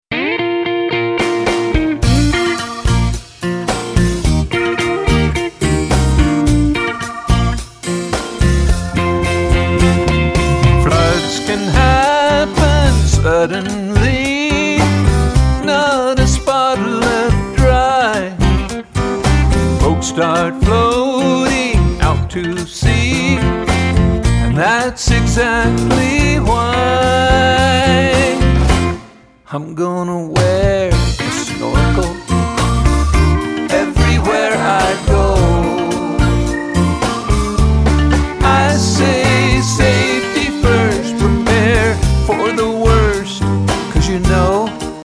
clever, cheeky, laugh-out-loud funny songs